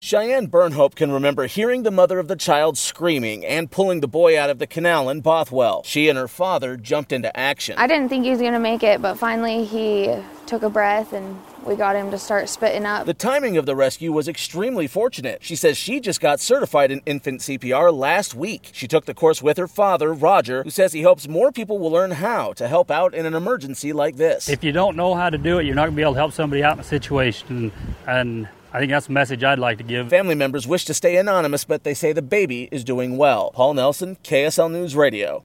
Rescuers describe saving a baby with CPR